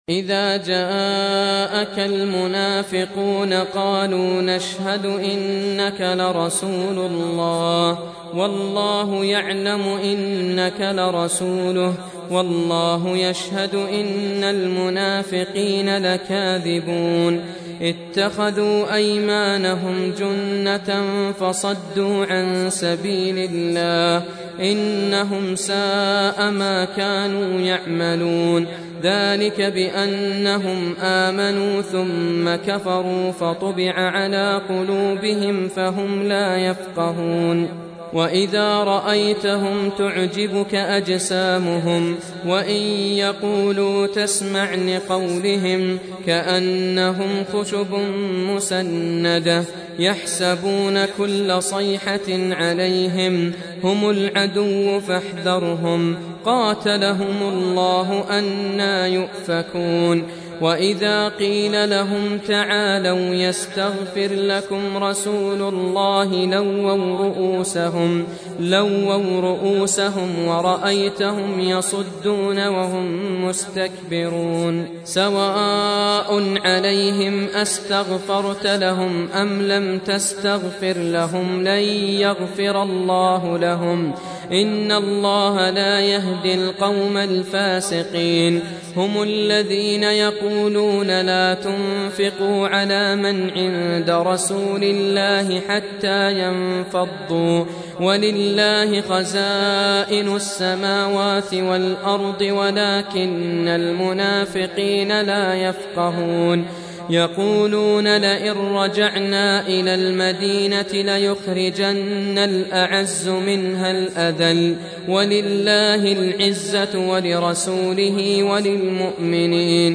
Surah Sequence تتابع السورة Download Surah حمّل السورة Reciting Murattalah Audio for 63. Surah Al-Munafiq�n سورة المنافقون N.B *Surah Includes Al-Basmalah Reciters Sequents تتابع التلاوات Reciters Repeats تكرار التلاوات